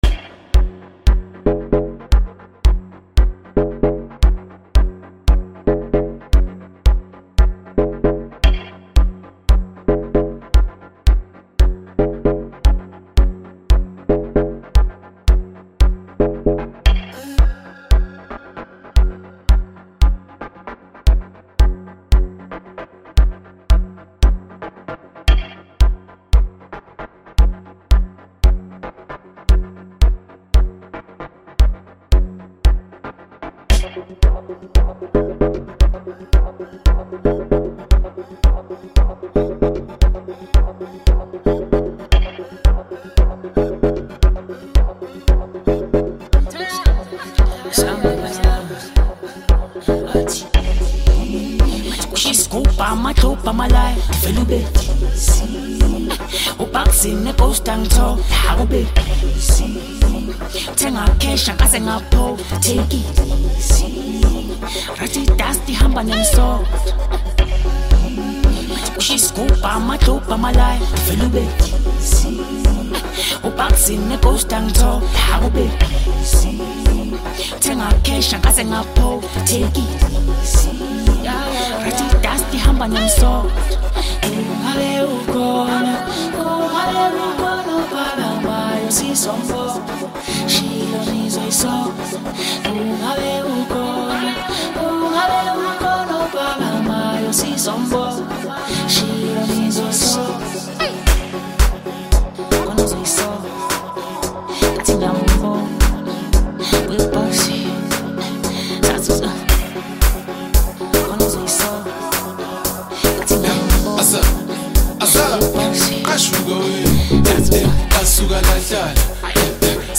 January 5, 2026 admin Amapiano 0